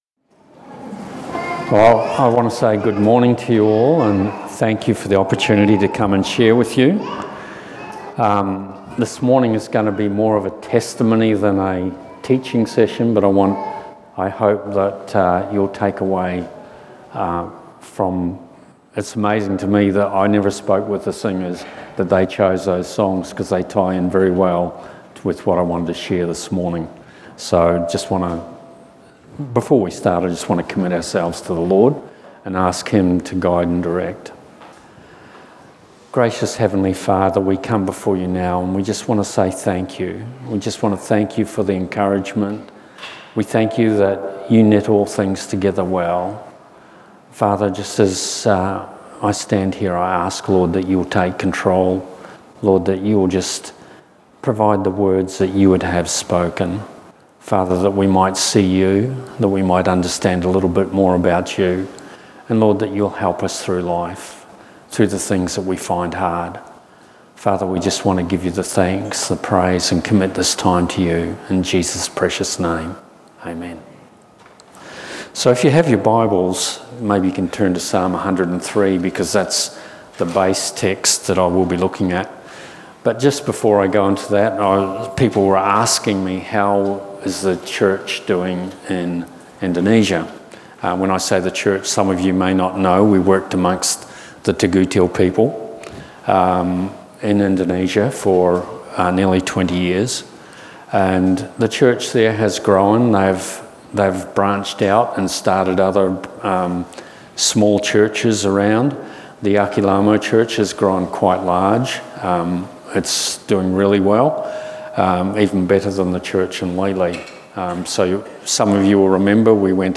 Psalm 103 Service Type: Family Service Topics